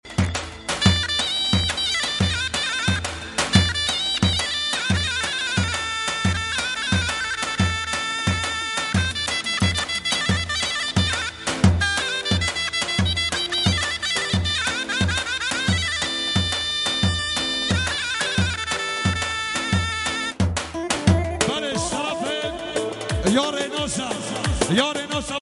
Davul zurna